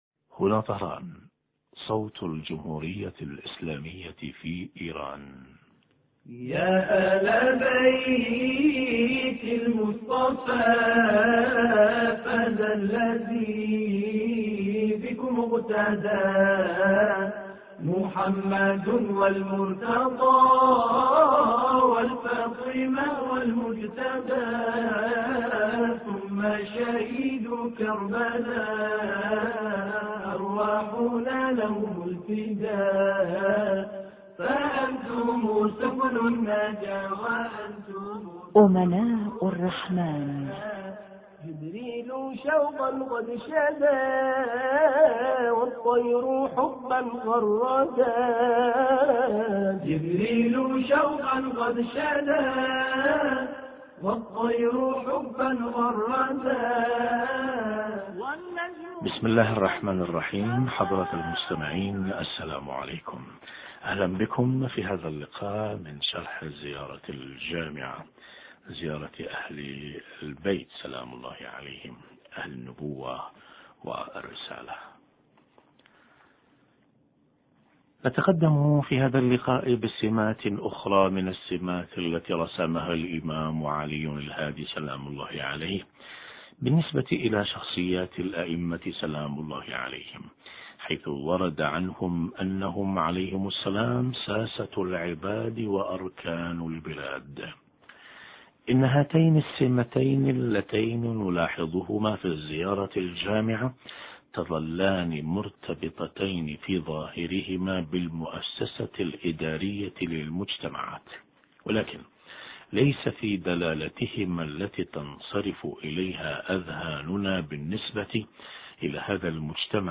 أما الآن نتابع تقديم برنامج امناء الرحمن بهذا الاتصال الهاتفي